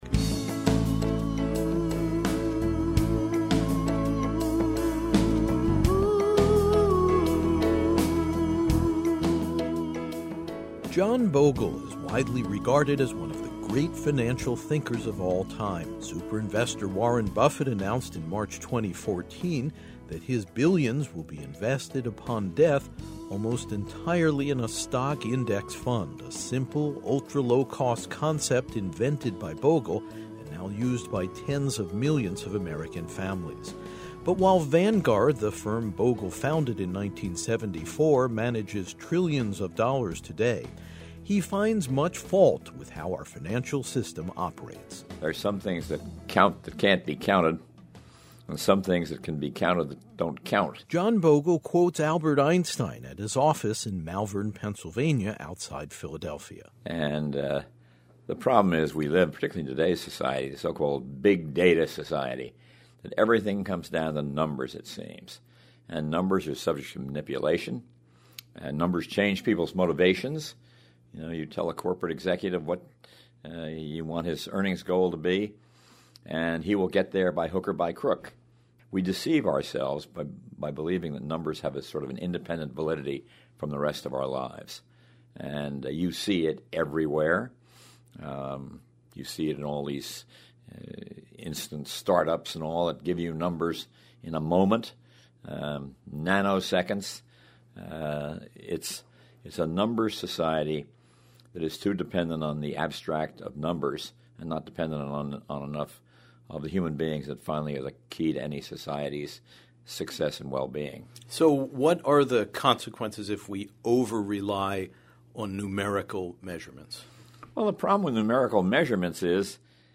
And in his gentlemanly manner as heard in this episode of Humankind, Bogle offers a devastating critique of high finance.